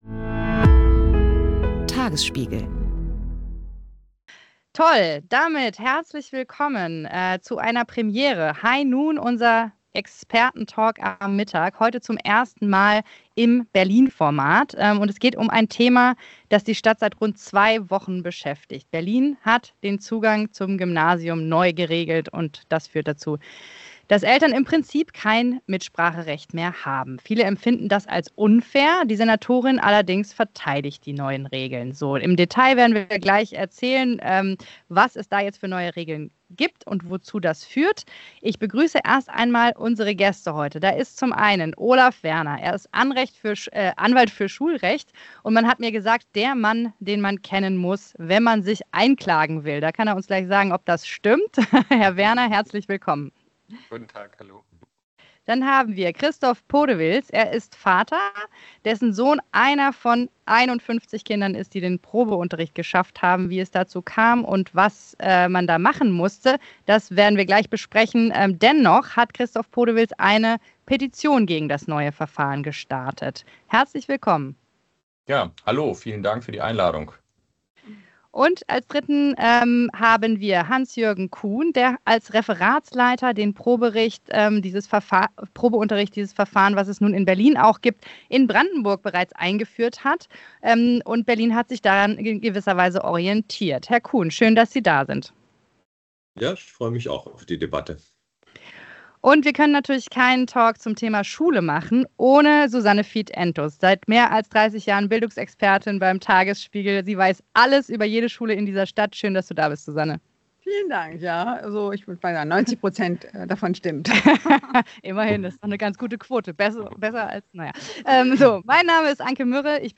Wir diskutieren über die Reform